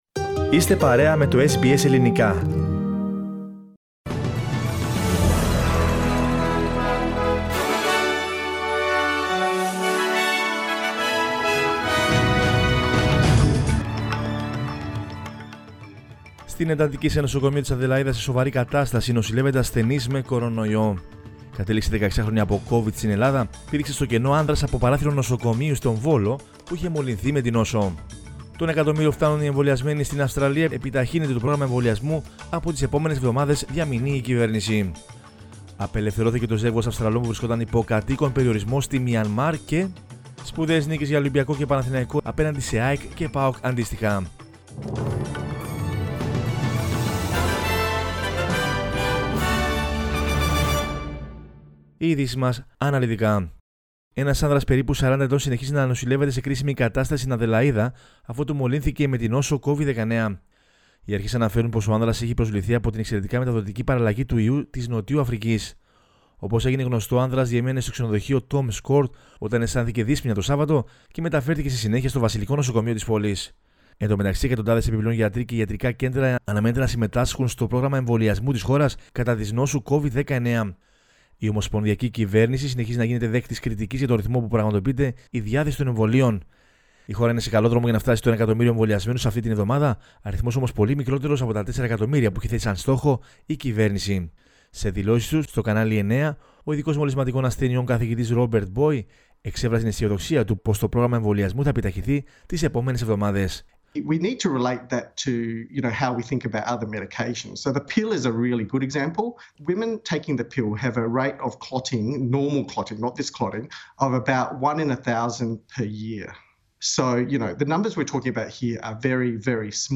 News in Greek from Australia, Greece, Cyprus and the world is the news bulletin of Monday 5 April 2021.